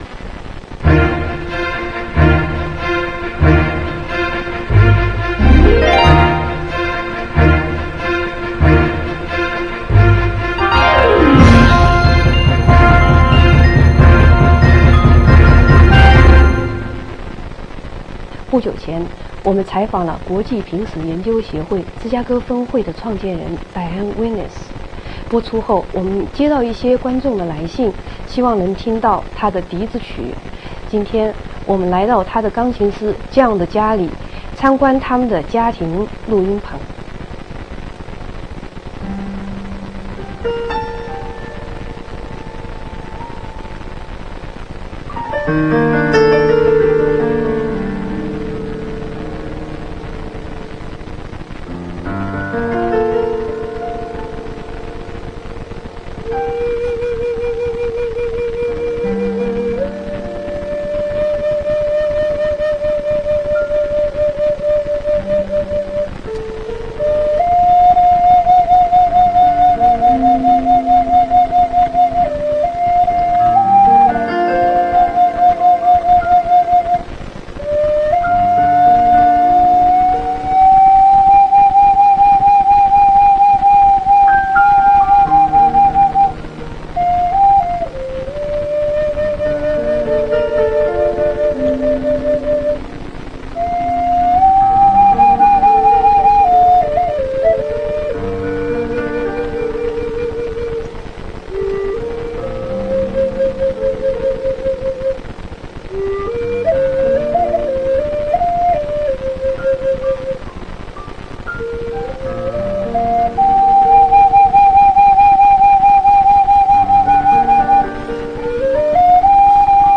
一個外國女性覺得她能夠演奏印地安的笛子是跟她前世有關的一段訪談。